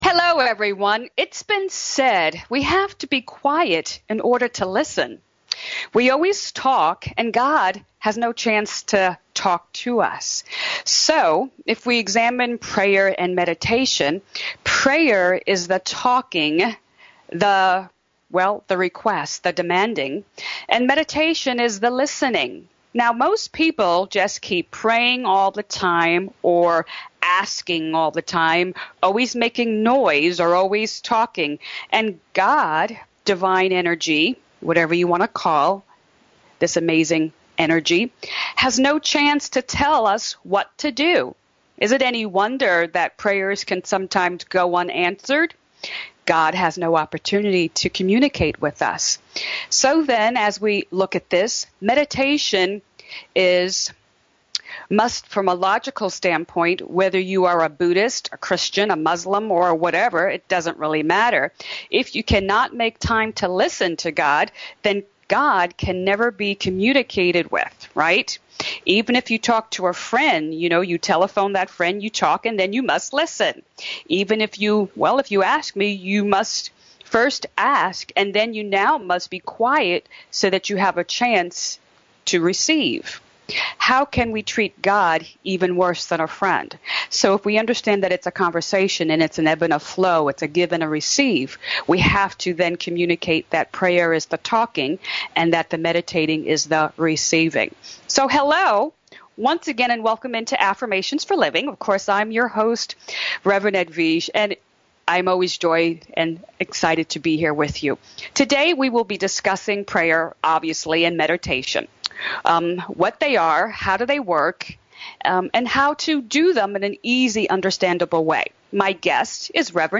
Each week you’re invited to join in on our upbeat discussion as we look deeper into spiritual based principles that can change your life.